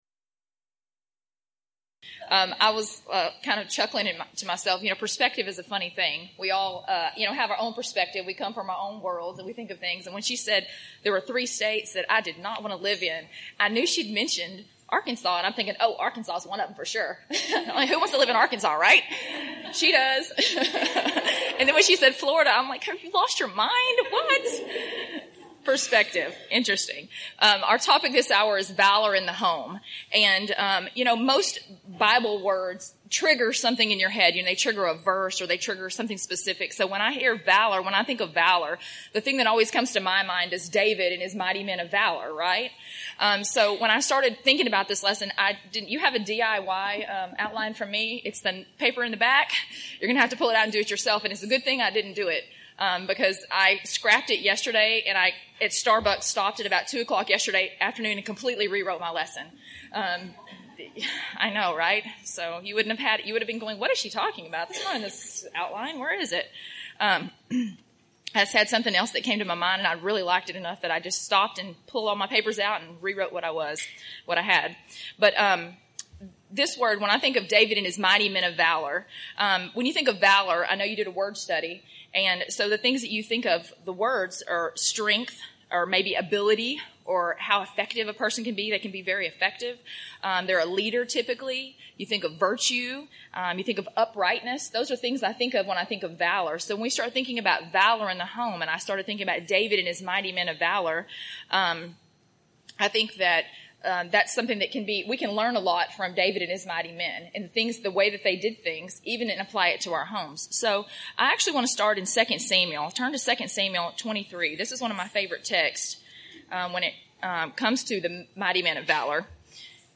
Event: 1st Annual Women of Valor Retreat
Ladies Sessions